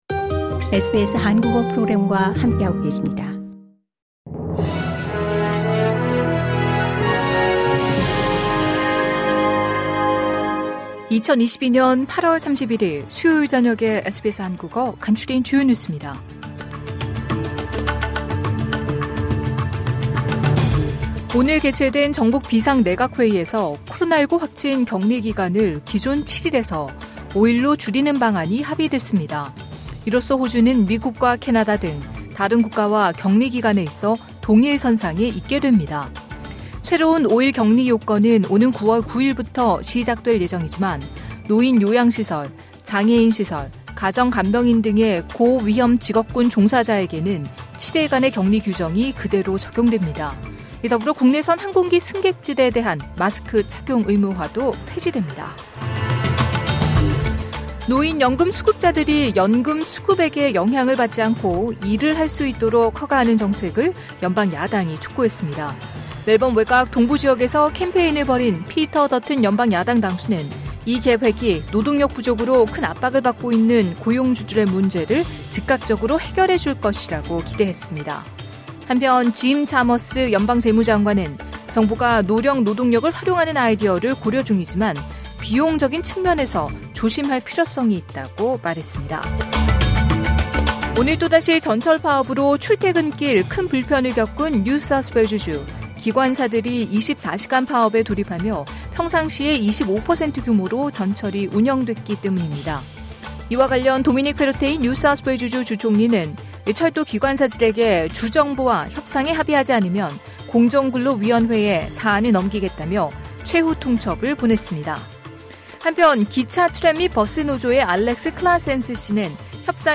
SBS 한국어 저녁 뉴스: 2022년 8월 31일 수요일